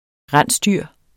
Udtale [ ˈʁanˀsˌdyɐ̯ˀ ]